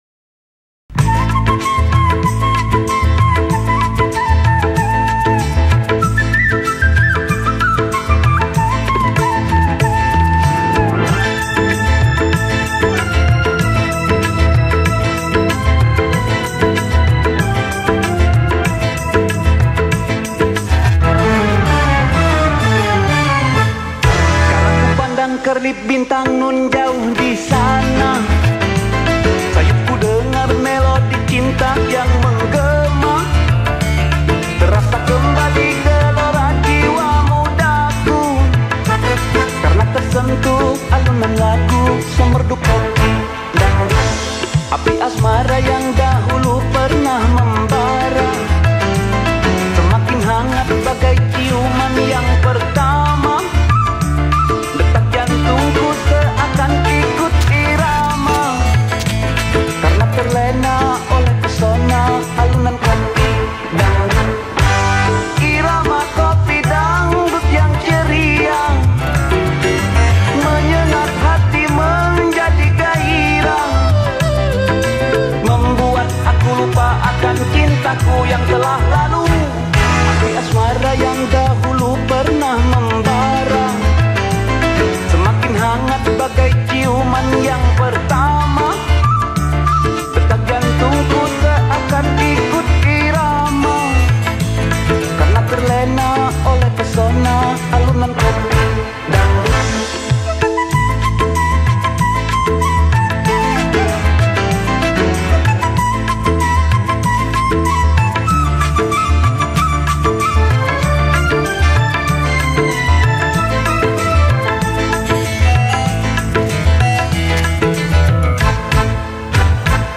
Indonesian song